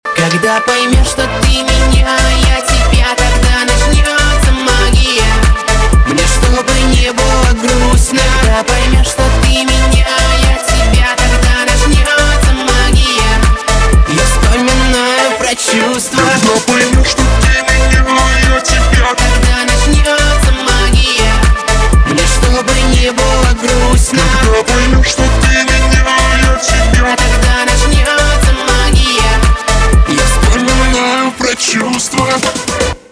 поп
битовые , басы , качающие , танцевальные